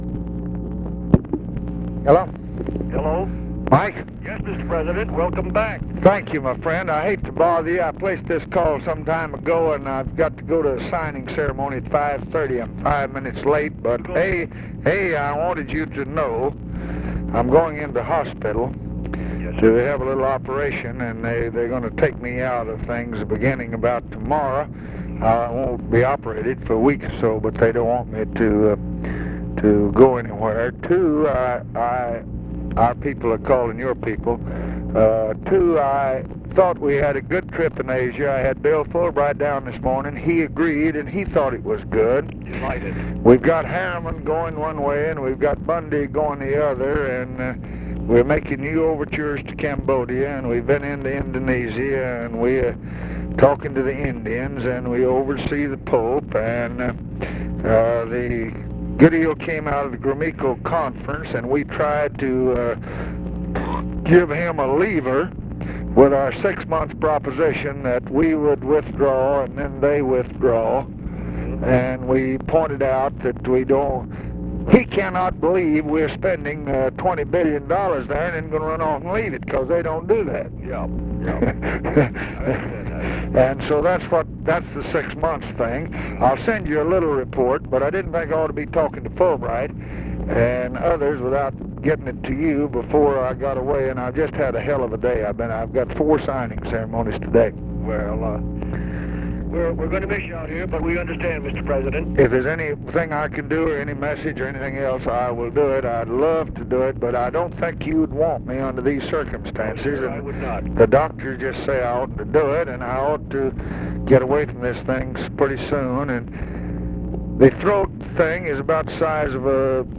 Conversation with MIKE MANSFIELD, November 3, 1966
Secret White House Tapes